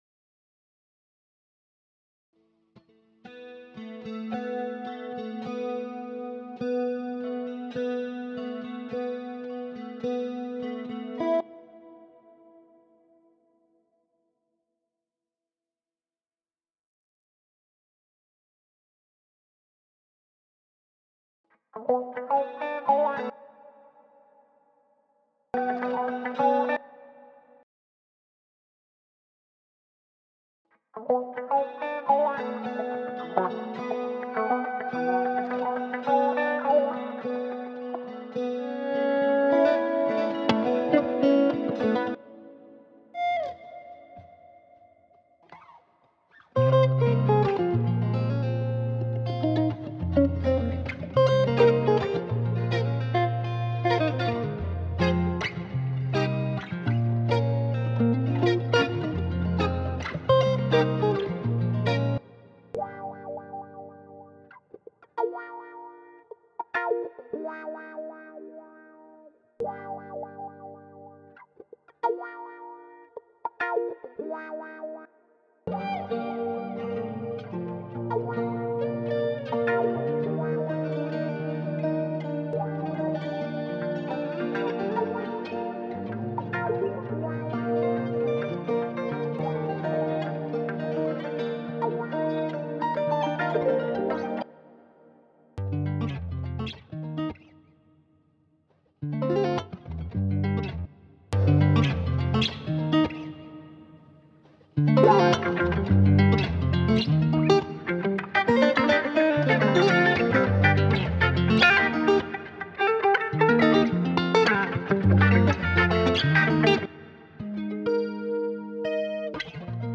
– Over 550+ Guitar Loops